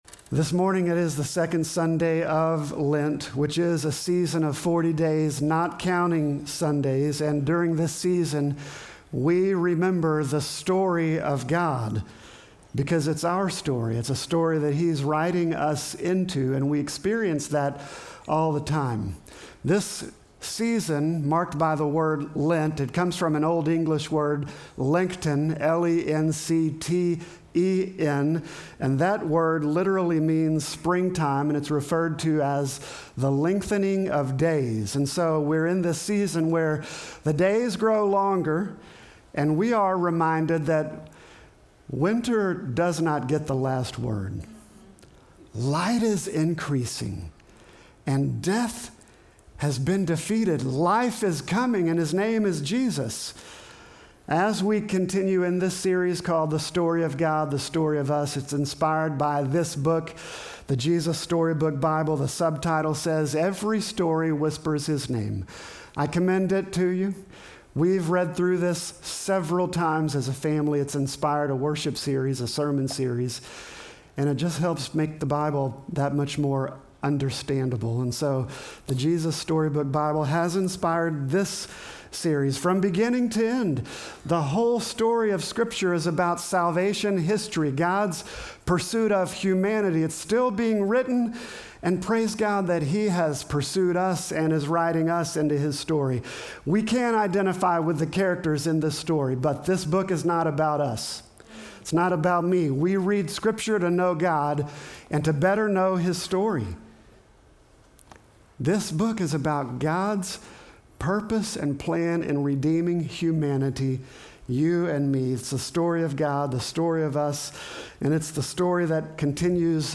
Sermon text: Joshua 5:13-6:5